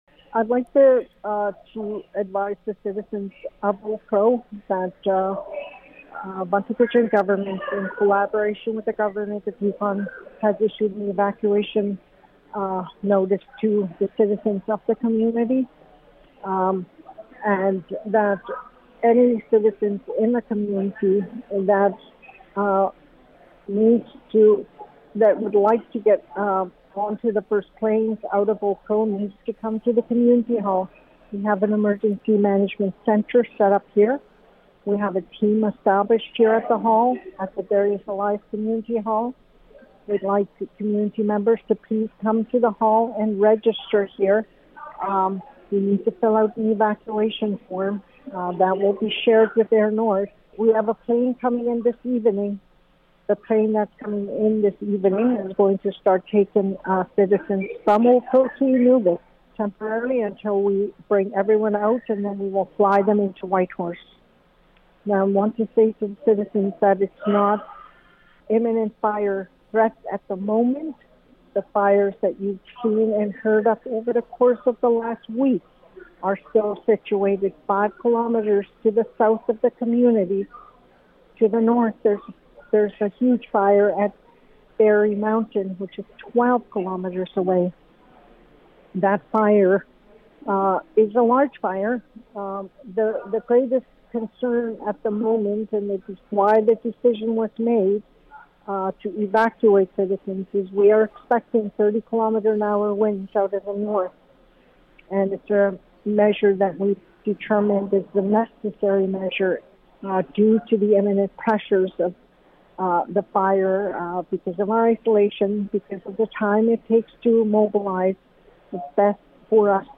The audio clip below contains Chief Pauline Frost's full comments on the evacuation.